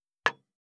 193,グラス,コップ,工具,小物,雑貨,コトン,トン,ゴト,ポン,ガシャン,ドスン,ストン,カチ,タン,バタン,スッ,サッ,コン,ペタ,
コップ効果音物を置く